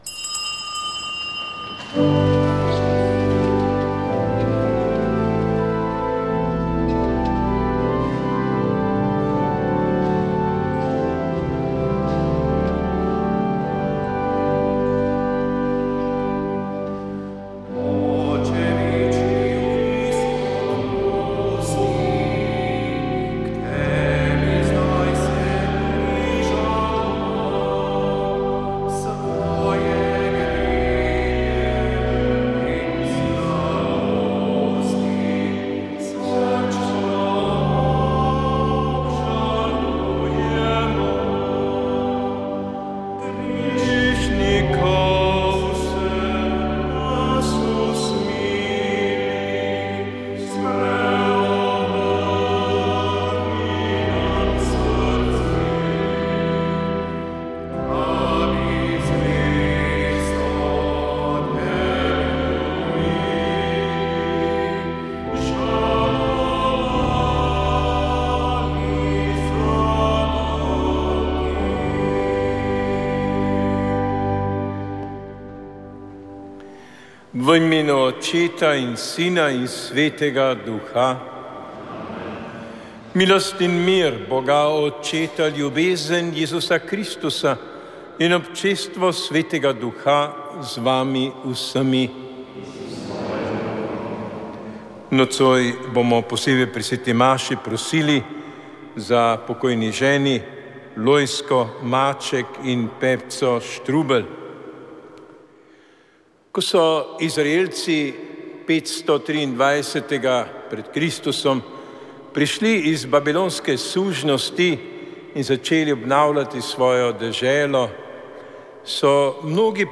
Sveta maša
Sv. maša iz stolne cerkve sv. Janeza Krstnika v Mariboru dne 6. 12.
Iz mariborske stolnice smo na drugo adventno nedeljo prenašali sveto mašo, ki jo je daroval mariborski nadškof Alojzij Cvikl.